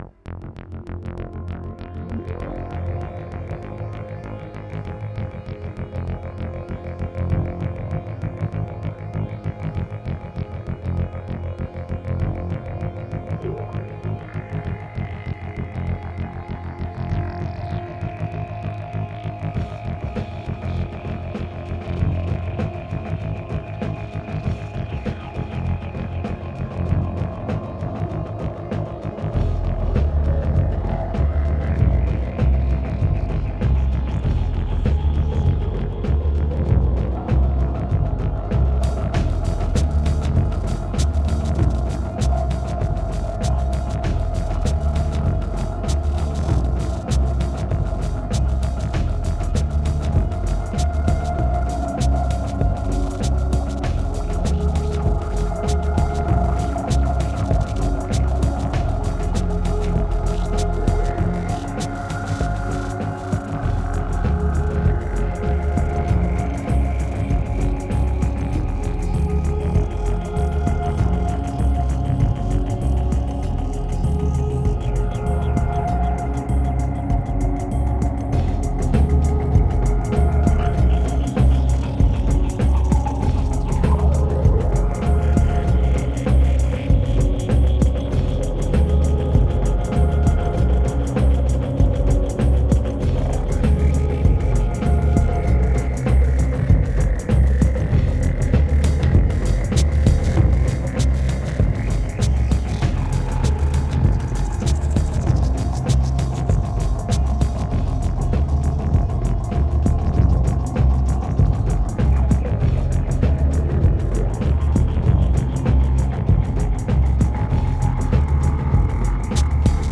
2354📈 - 55%🤔 - 98BPM🔊 - 2010-12-18📅 - 51🌟